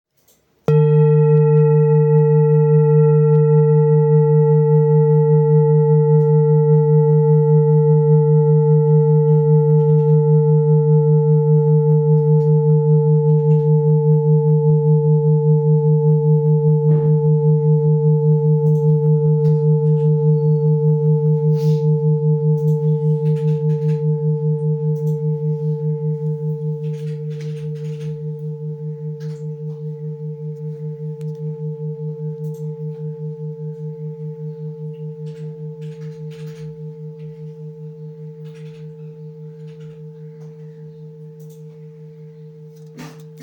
Singing Bowl, Buddhist Hand Beaten, with Fine Etching Carving
Material Seven Bronze Metal